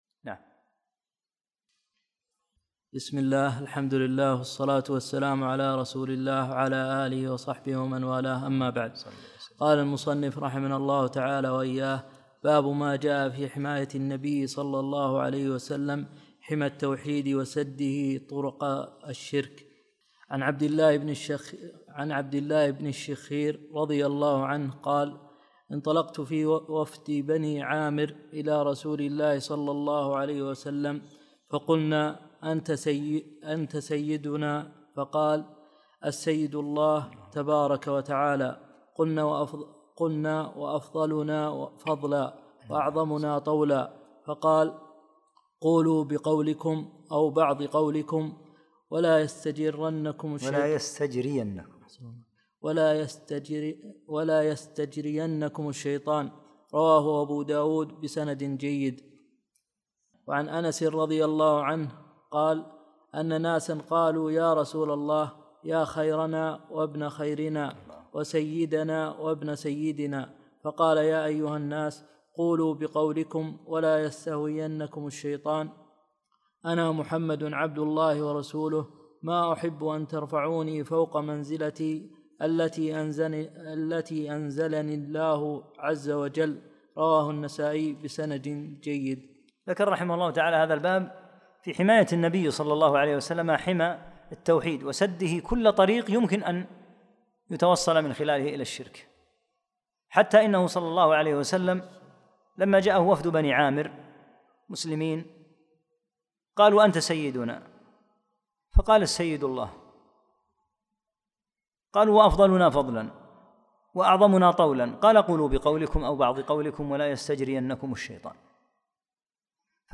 55- الدرس الخامس والخمسون